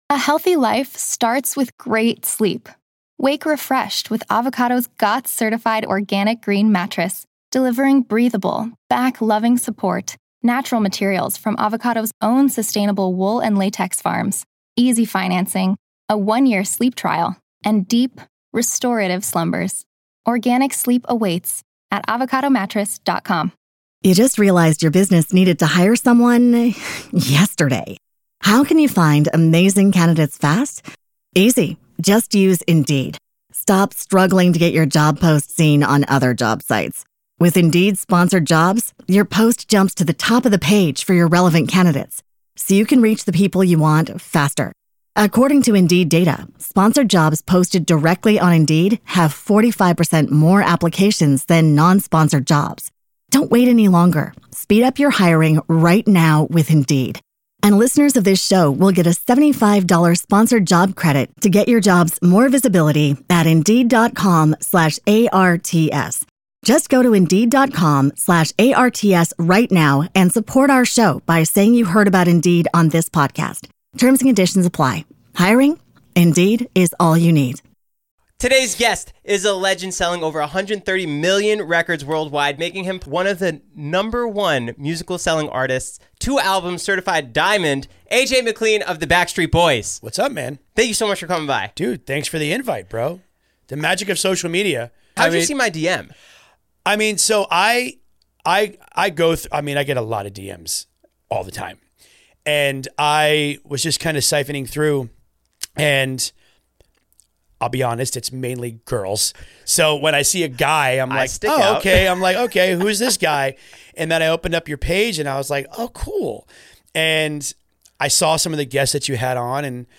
Society & Culture, Tv Reviews, Comedy Interviews, Film Interviews, Comedy, Tv & Film, Education, Hobbies, Music Commentary, Music Interviews, Relationships, Leisure, Health & Fitness, Self-improvement, Music, Fitness